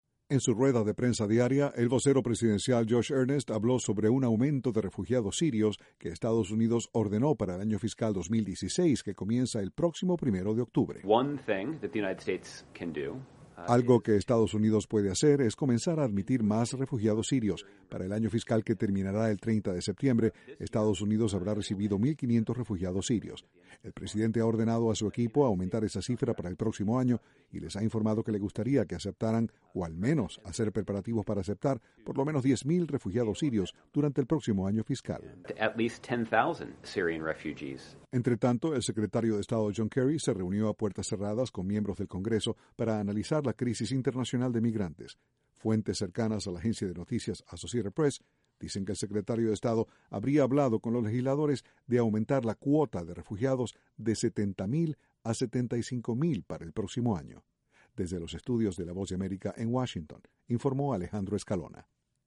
La Casa Blanca informó este jueves que Estados Unidos aumentará el número de refugiados sirios que aceptará en 2016. Desde la Voz de América, Washington